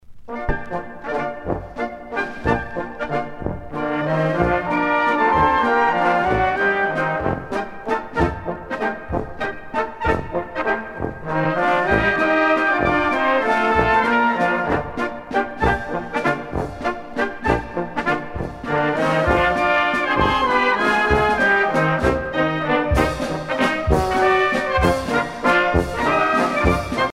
danse : valse viennoise
Pièce musicale éditée